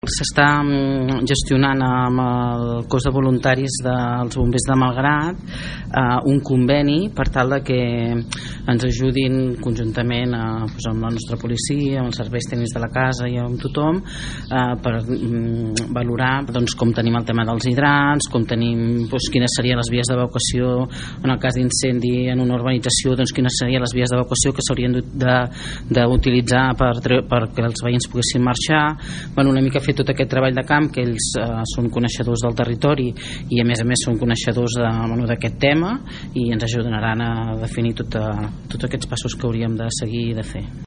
Susanna Pla, regidora de Governació, explica que s’està fent un treball de camp conjunt entre Policia Local, Serveis Tècnics del consistori i els bombers per revisar els hidrants que hi ha al poble o l’estat de les vies d’evacuació, entre altres.